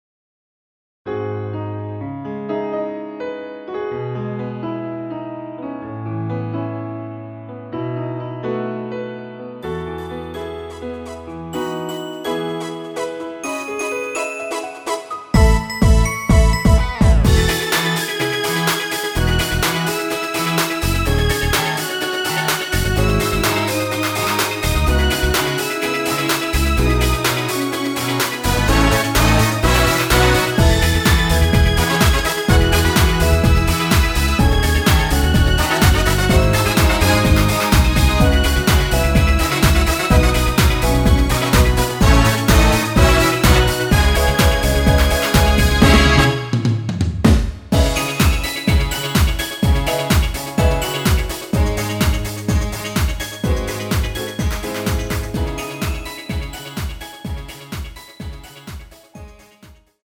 여성분이 부르실수 있는 키로 제작 하였습니다.(미리듣기 참조)
앞부분30초, 뒷부분30초씩 편집해서 올려 드리고 있습니다.
중간에 음이 끈어지고 다시 나오는 이유는